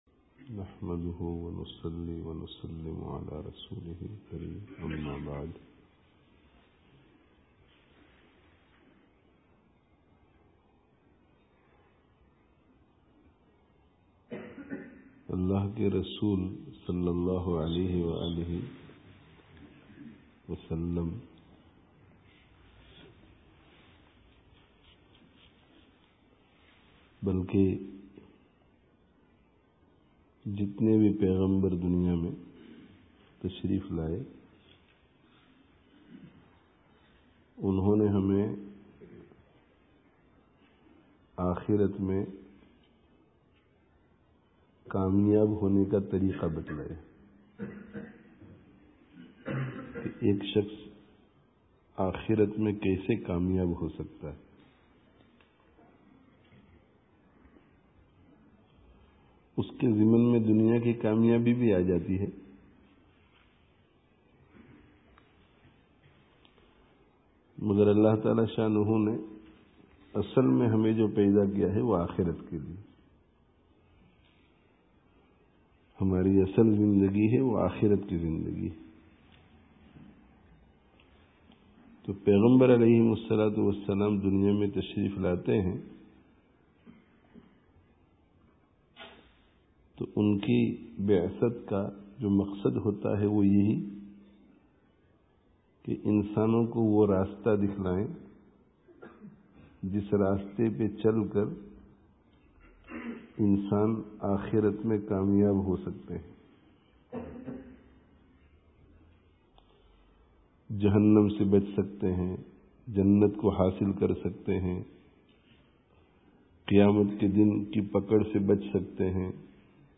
friday tazkiyah gathering